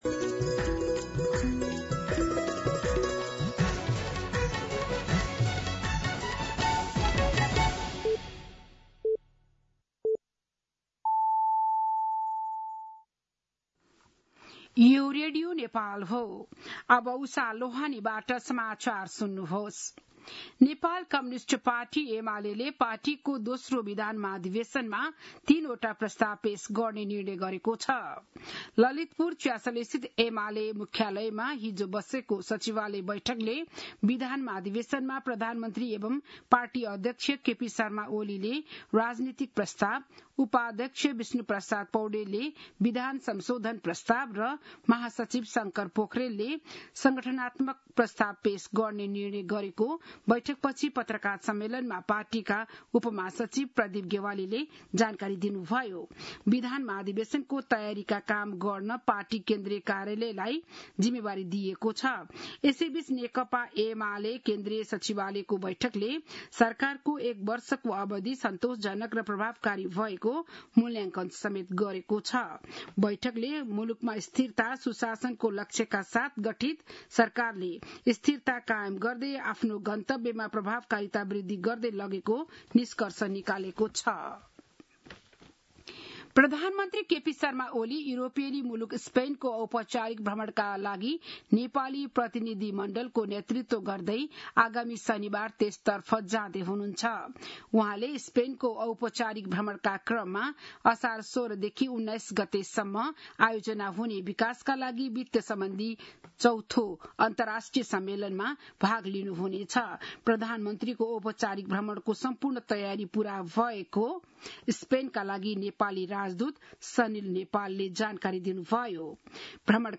बिहान ११ बजेको नेपाली समाचार : १२ असार , २०८२